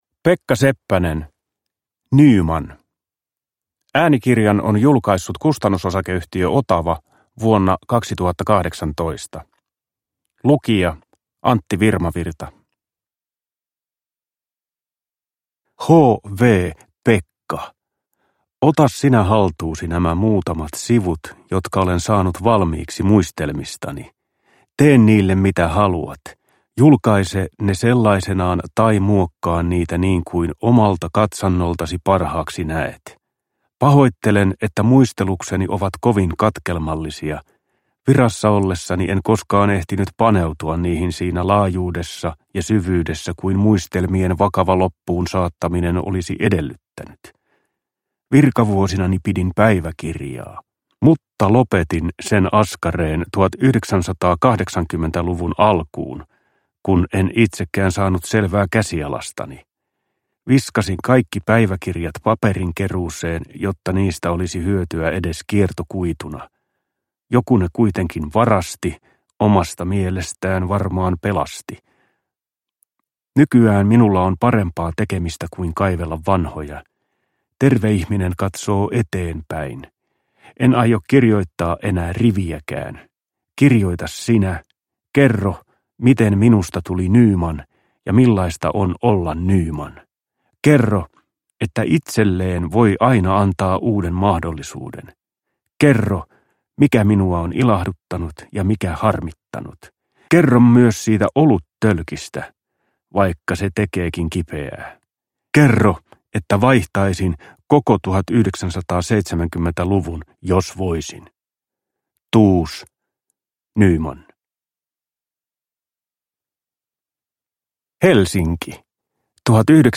Uppläsare: Antti Virmavirta